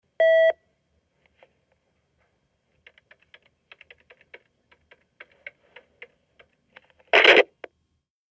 Intercom beep & in - line hang up